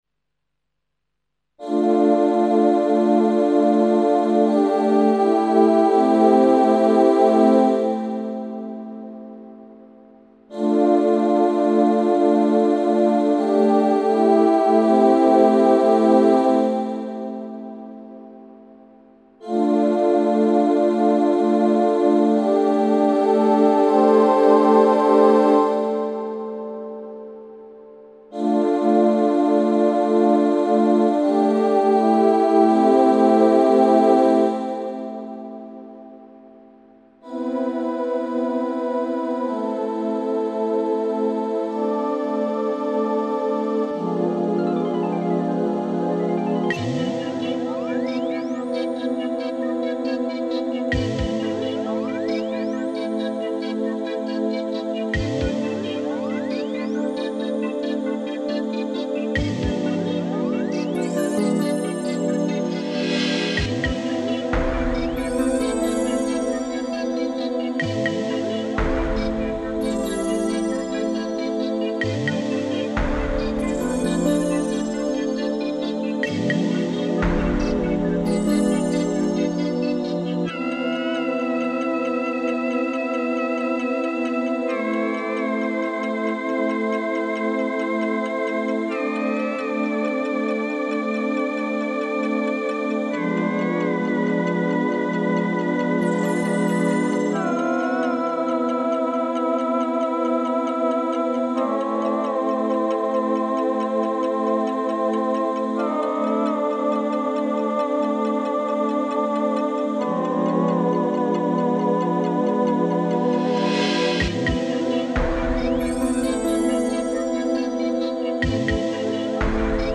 AMBIENT MUSIC ; CINEMATIC MUSIC